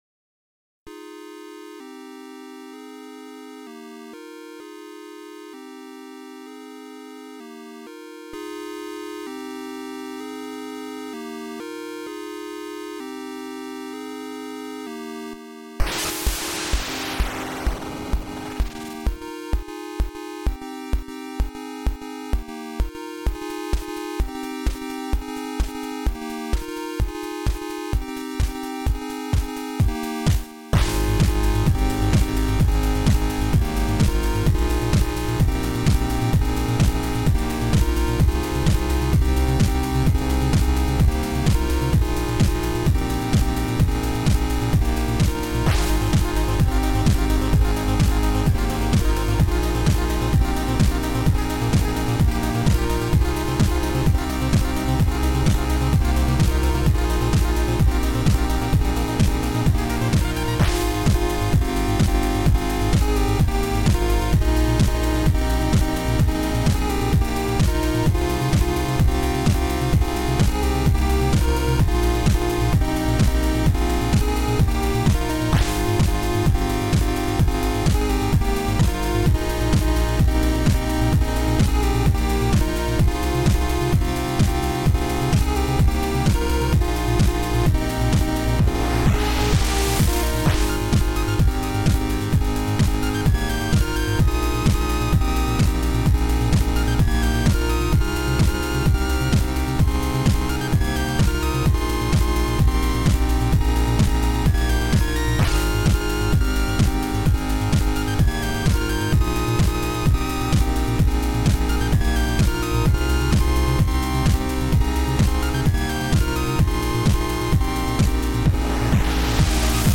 Made with 0CCFamiTracker 0.3.10.